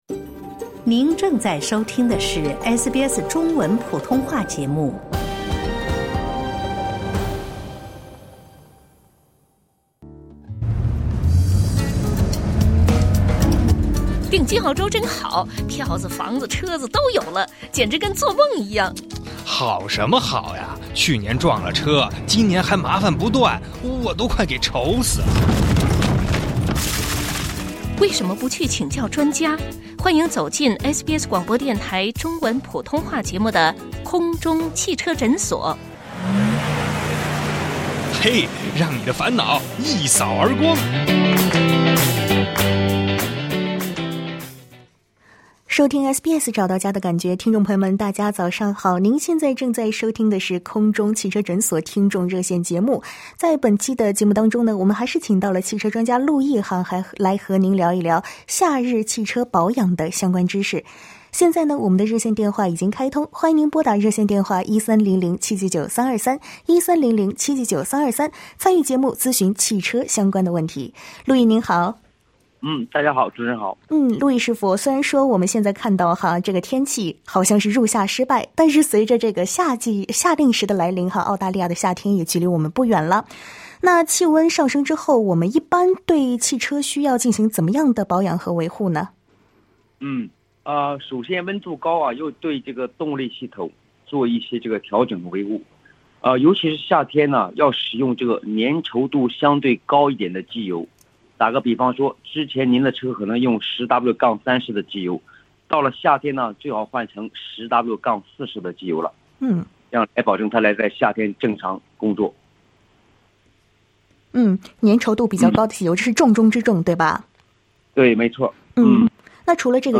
在本期《空中汽车诊所》听众热线节目中